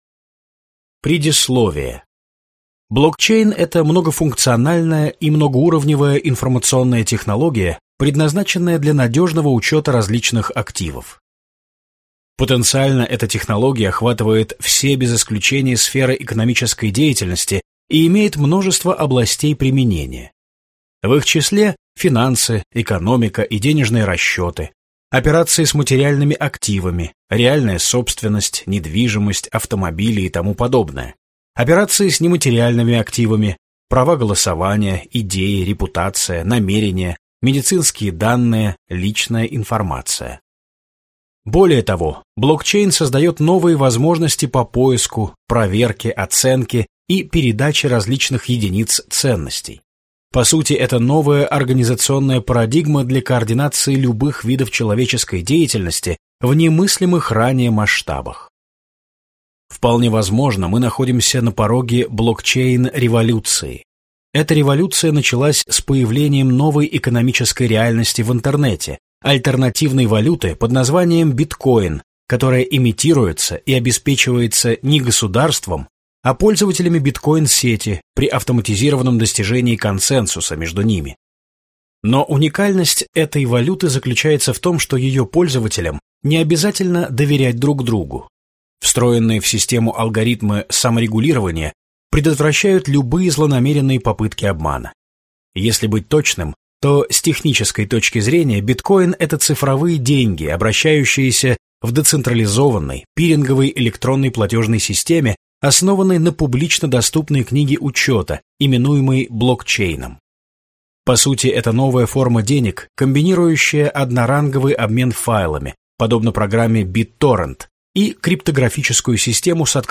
Аудиокнига Блокчейн. Схема новой экономики | Библиотека аудиокниг